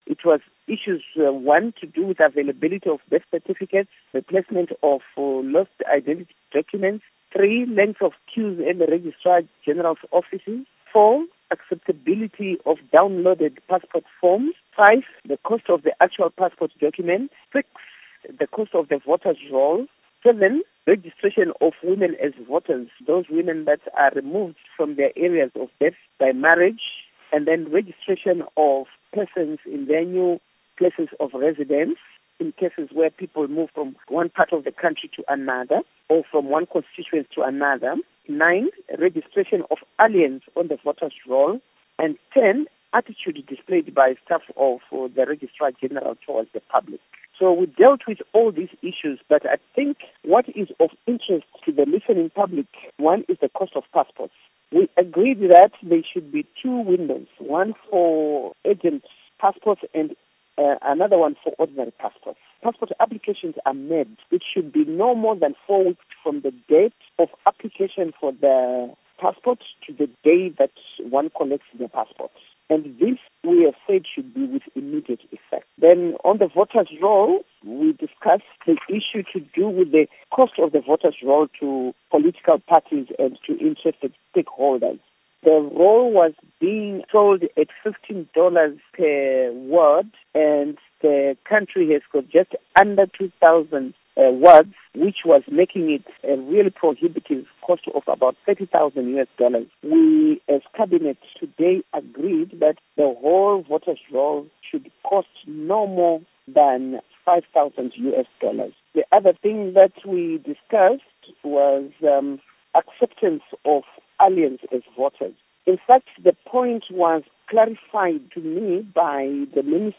Interview With Theresa Makone